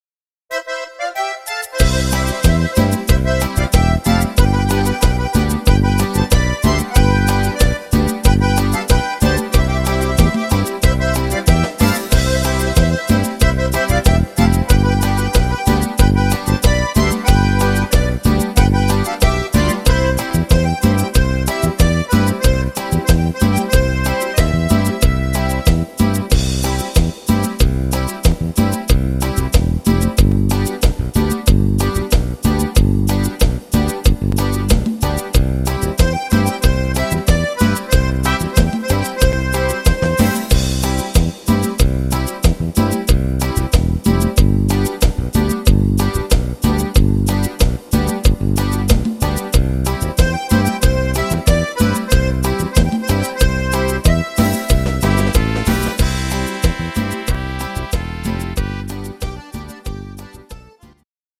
Mexican Line Dance